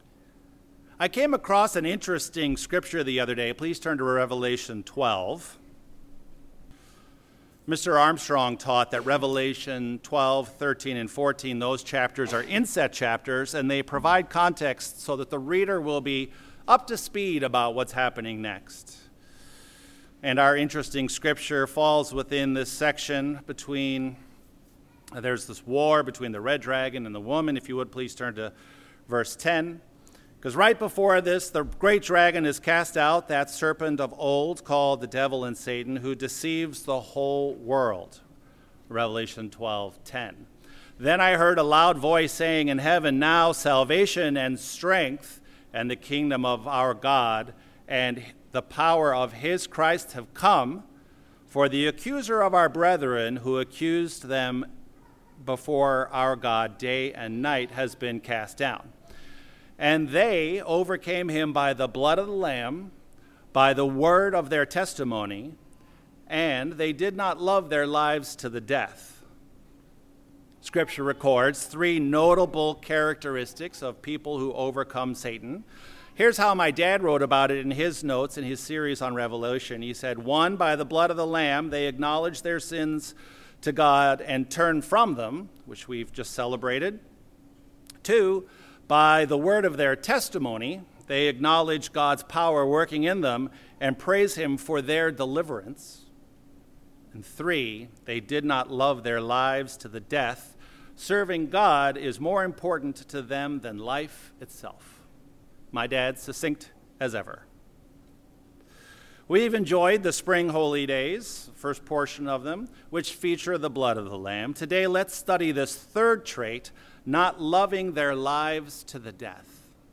Like Christ, they focus on walking in love and giving of themselves in love and service to God and their neighbor. This sermon provides insight into living life in this fashion.
Given in Chicago, IL